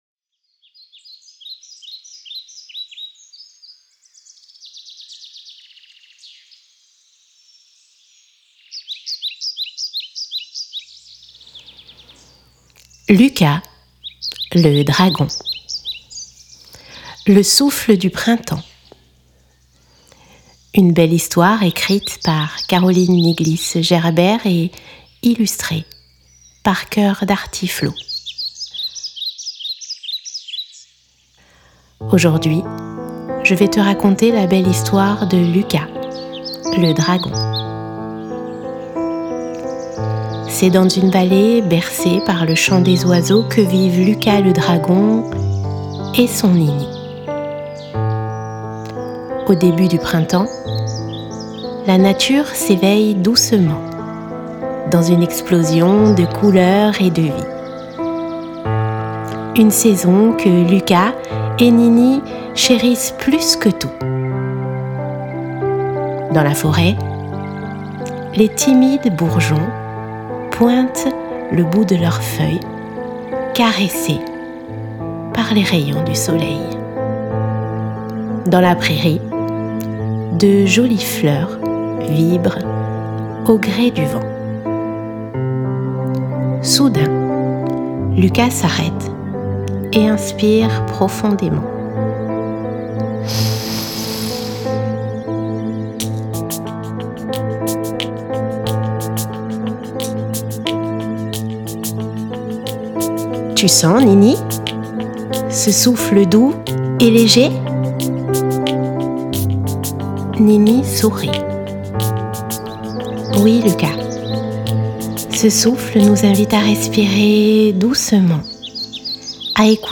Luka-le-souffle-du-printemps-livre-audio.mp3